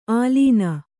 ♪ ālīna